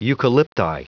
Prononciation du mot eucalypti en anglais (fichier audio)
Prononciation du mot : eucalypti